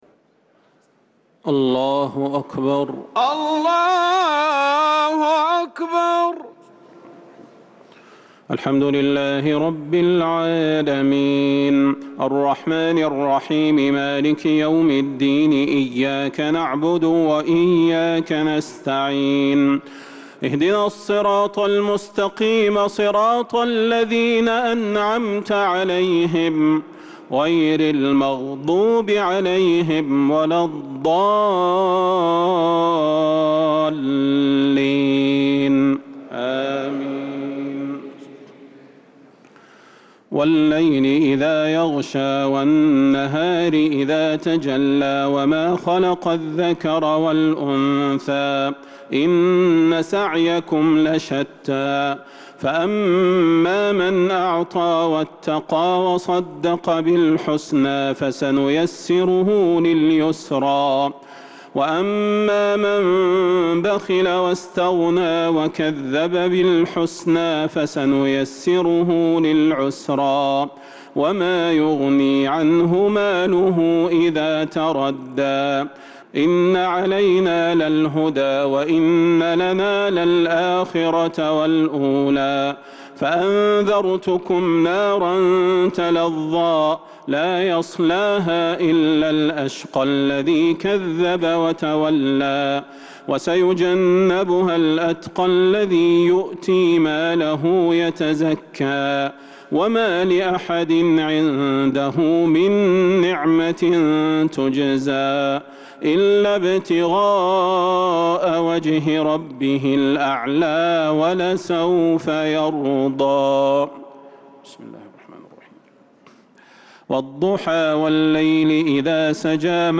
صلاة التراويح ليلة 29 رمضان 1443 للقارئ صلاح البدير - التسليمتان الأخيرتان صلاة التراويح